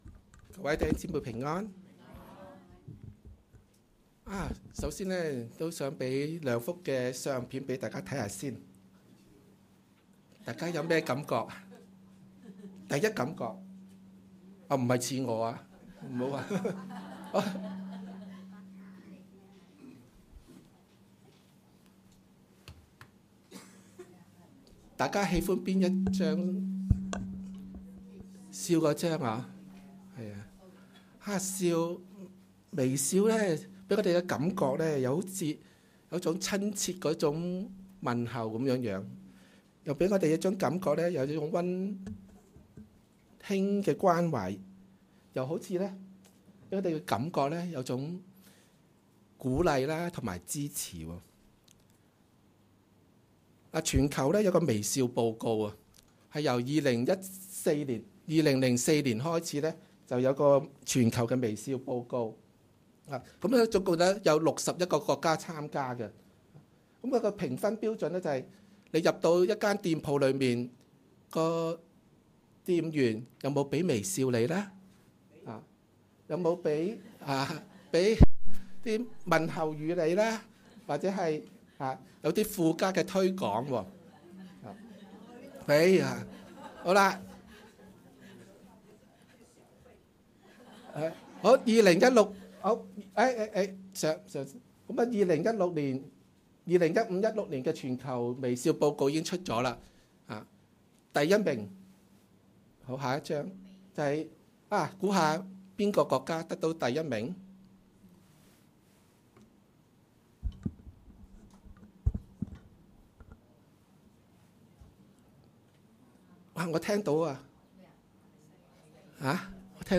2016年5月28日及29日崇拜